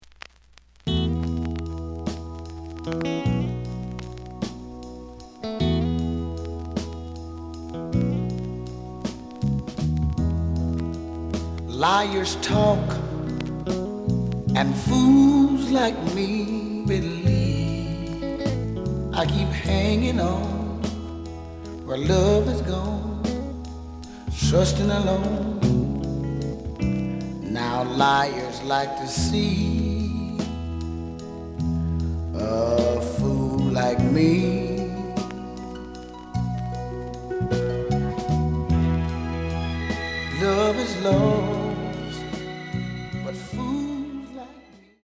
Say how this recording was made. The listen sample is recorded from the actual item.